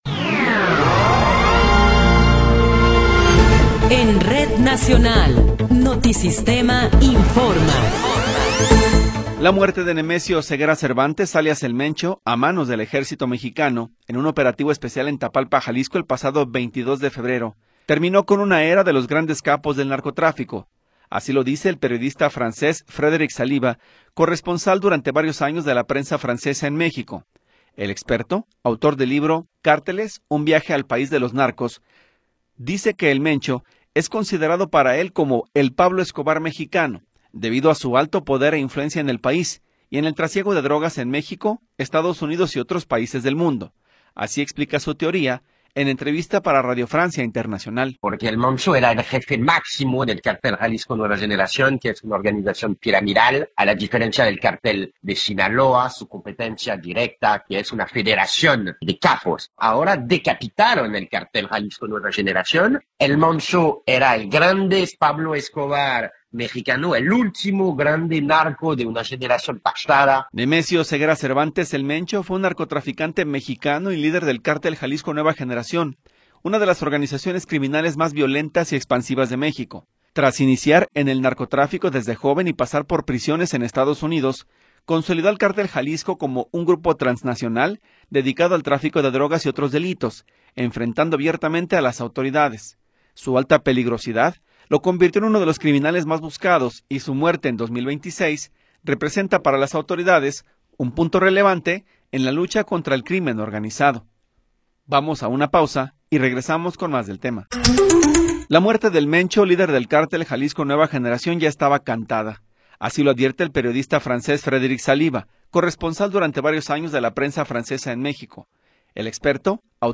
Noticiero 13 hrs. – 1 de Marzo de 2026